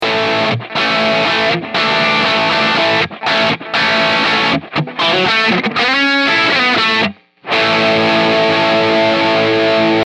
玄人系が好ミドル強調
ベース４、ミドル７、トレブル４
腰があり　輪郭がはっきりした感じがします。